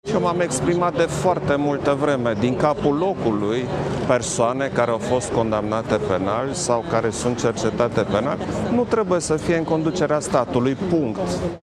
Klaus Iohannis, aflat în Suedia, la Göteborg – unde participă la summitul european social:
17nov-11-iohannis-despre-dragnea.mp3